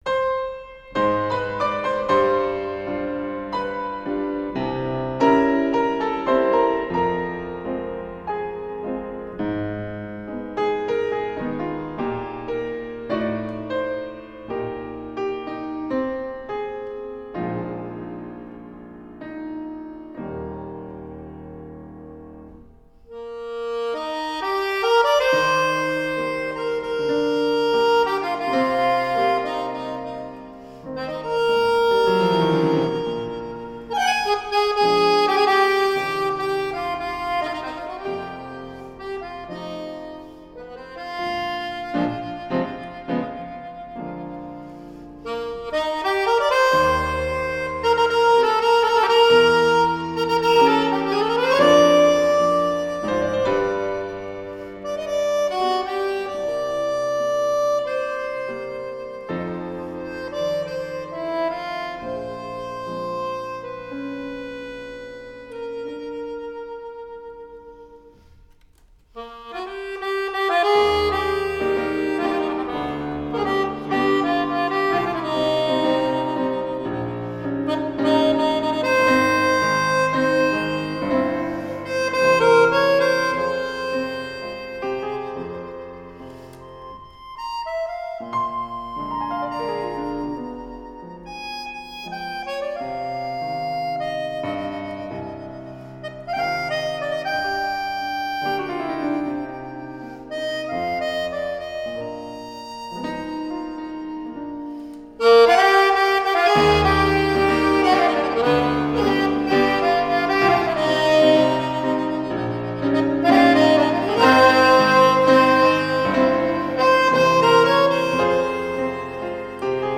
Fisarmonica
Pianoforte